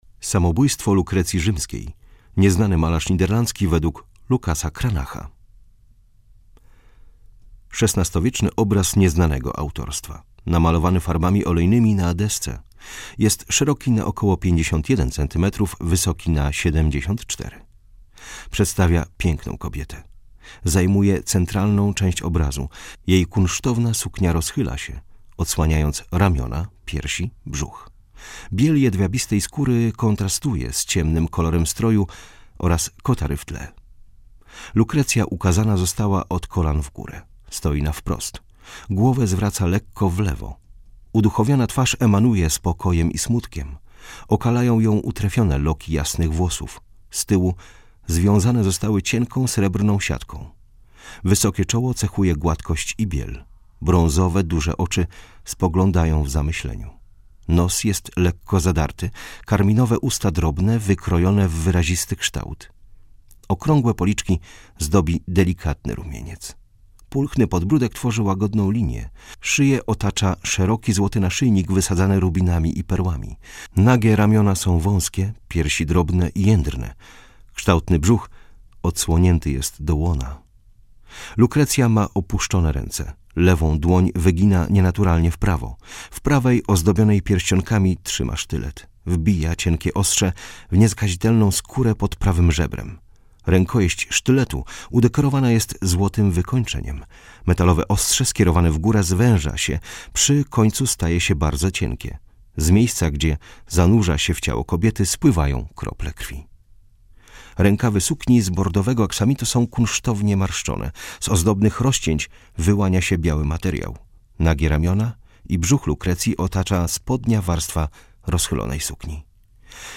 Audiodeskrypcja - EUROPEUM